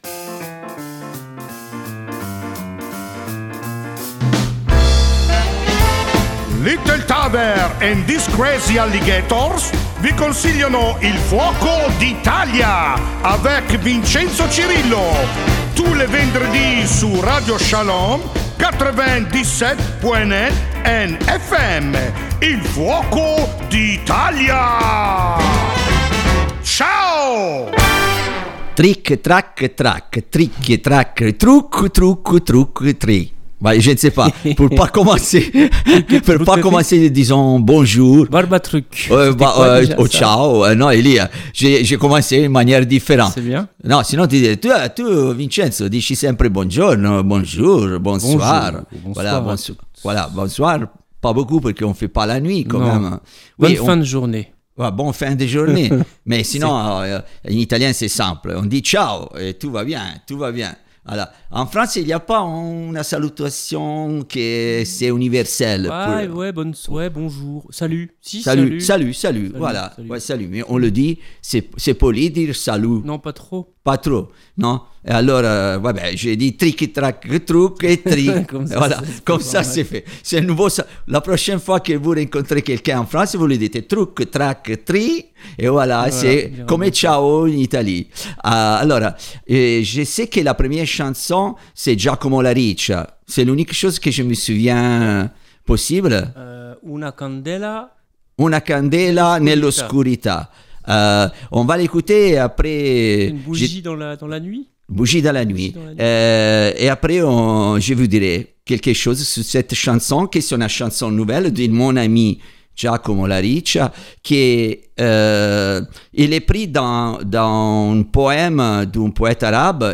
Nos studios transformés en sauna le temps d'enregister cette nouvelle émission d'Il Fuoco d'Italia.